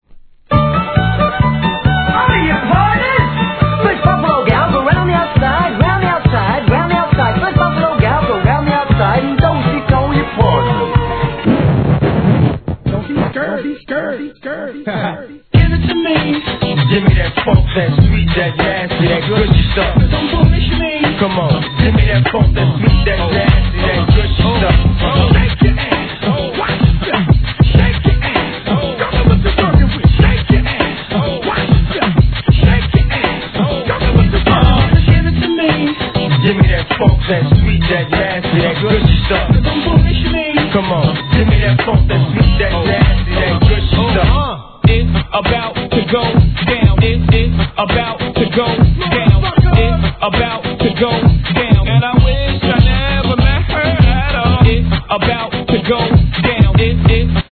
HIP HOP/R&B
巧みな声ネタサンプリング術で盛り上げます!